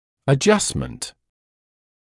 [ə’ʤʌstmənt][э’джастмэнт]подгонка, пришлифовка; регулировка; юстировка